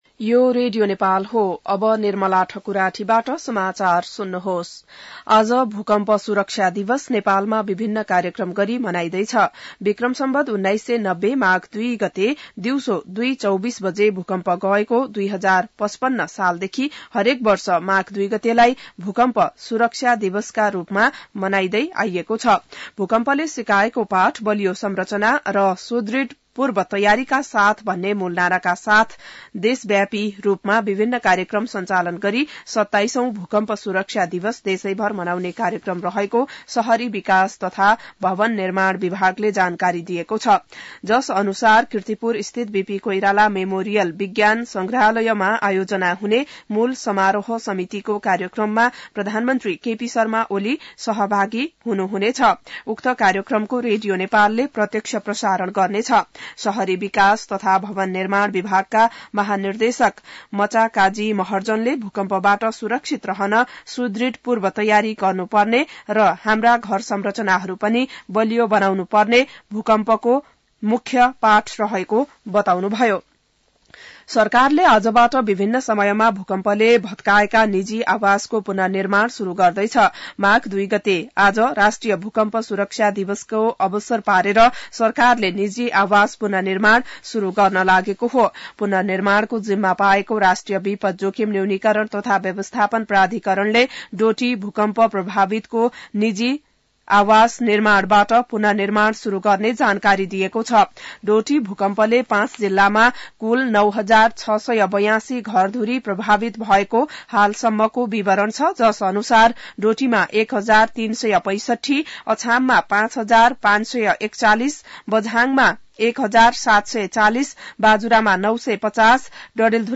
An online outlet of Nepal's national radio broadcaster
बिहान १० बजेको नेपाली समाचार : ३ माघ , २०८१